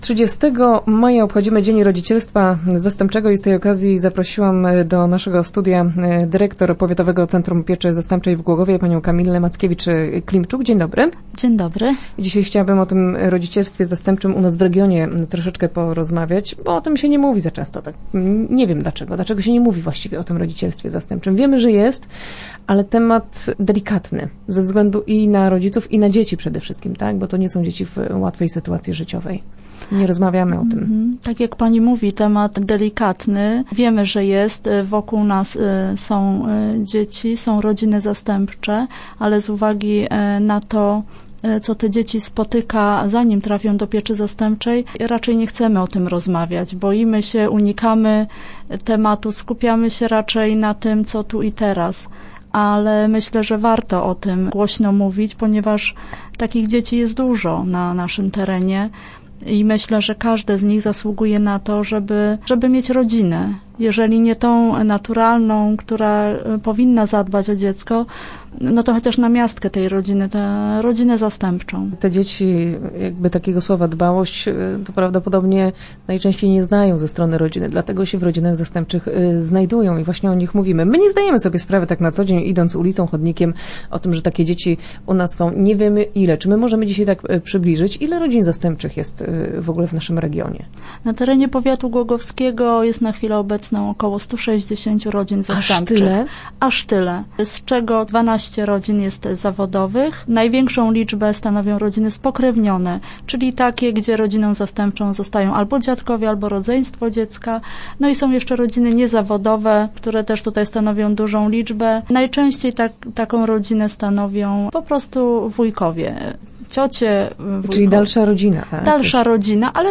Start arrow Rozmowy Elki arrow Dzień Rodzicielstwa Zastępczego